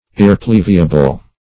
Search Result for " irrepleviable" : The Collaborative International Dictionary of English v.0.48: Irrepleviable \Ir`re*plev"i*a*ble\, Irreplevisable \Ir`re*plev"i*sa*ble\, a. (Law) Not capable of being replevied.